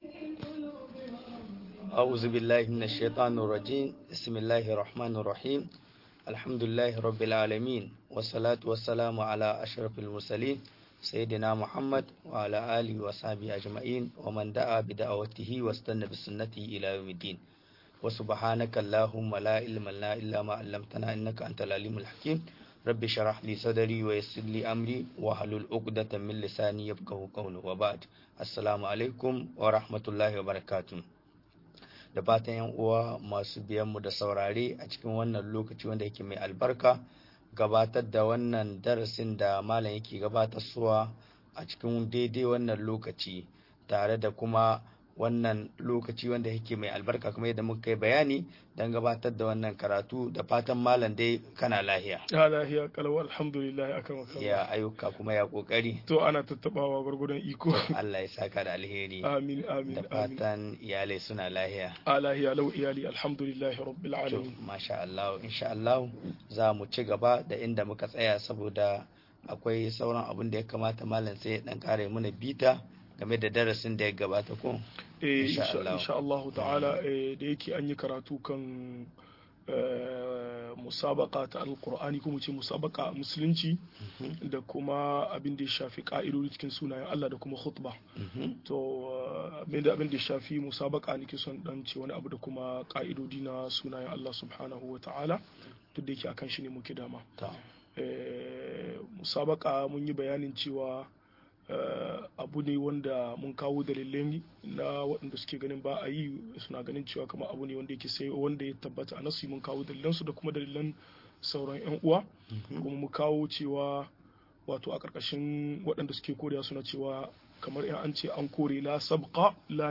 Sunayen Allah da siffofin sa-03 - MUHADARA